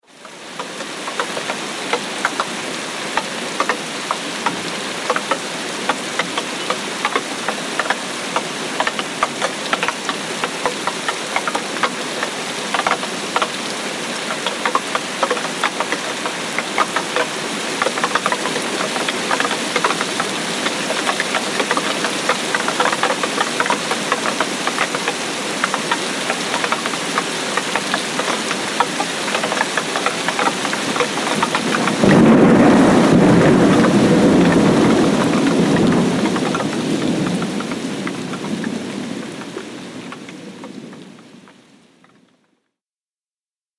دانلود آهنگ باران 10 از افکت صوتی طبیعت و محیط
دانلود صدای باران 10 از ساعد نیوز با لینک مستقیم و کیفیت بالا
جلوه های صوتی